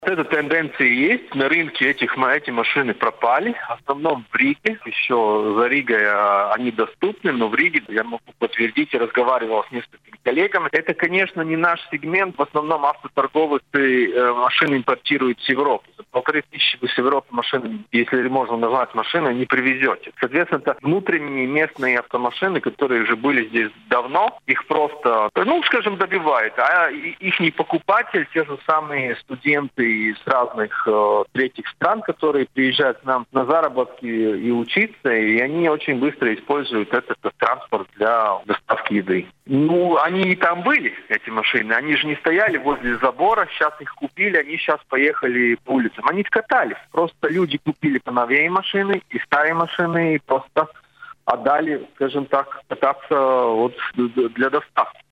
А сегодня на радио Baltkom ведущие обсуждали с экспертами целый пакет вопросов - говорили об уборке снега с улиц столицы, об освоении Латвией космоса,  о вторичном рынке автомашин.